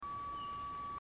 Form: Townsend's Solitaire
Location: Sand Ridge State Forest
Number of birds: one